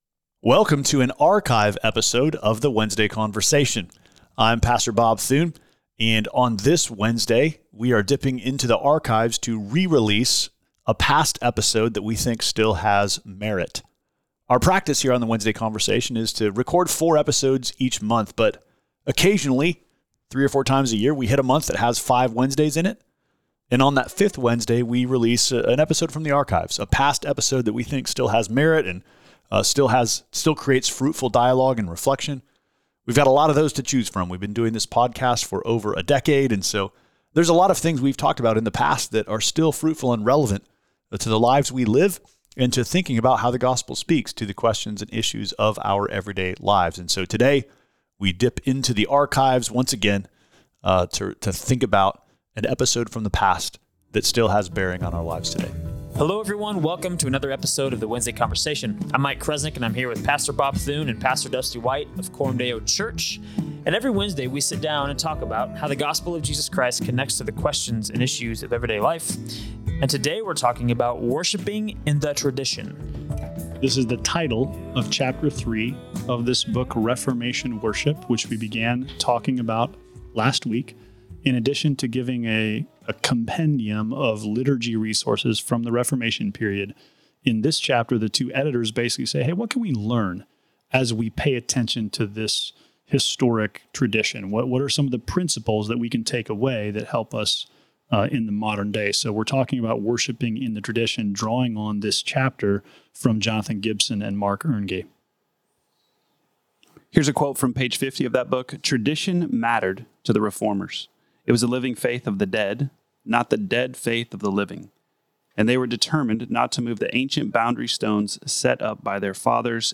A weekly conversation about how the gospel of Jesus Christ connects to the questions and issues of everyday life.